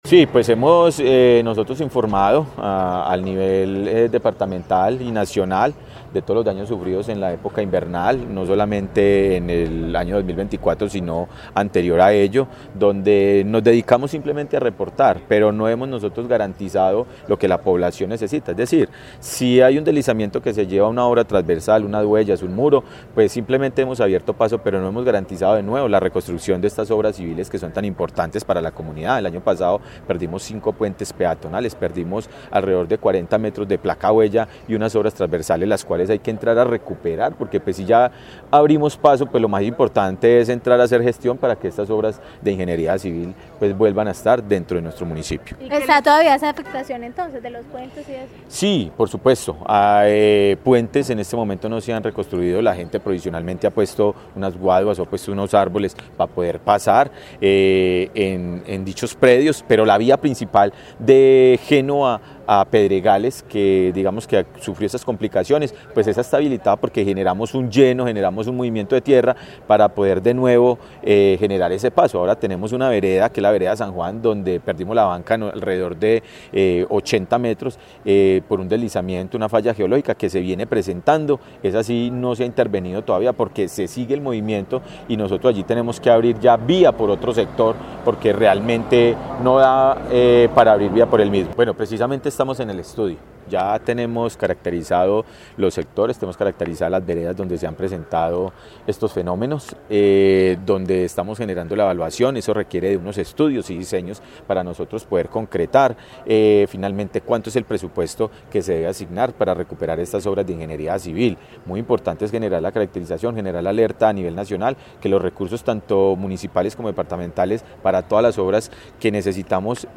Diego Fernando Sicua, Alcalde de Genova